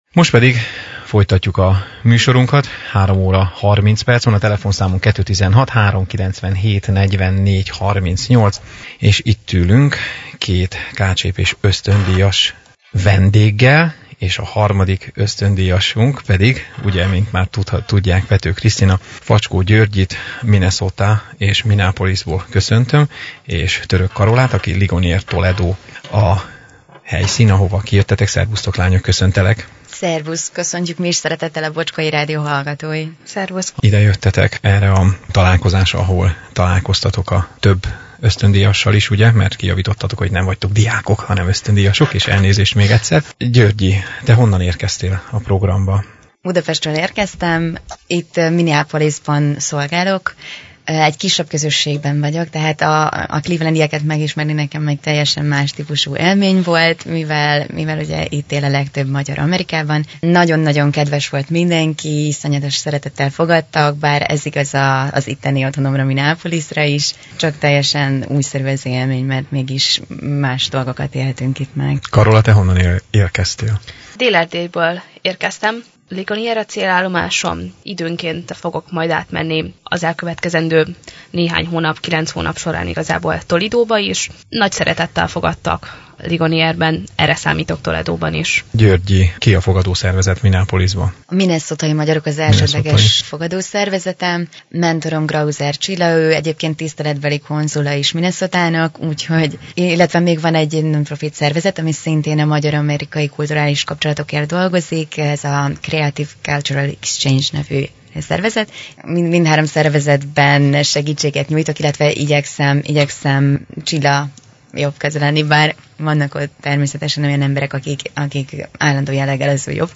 A november 27-i adásunknak két KCSP-s ösztöndíjas is a vendége volt.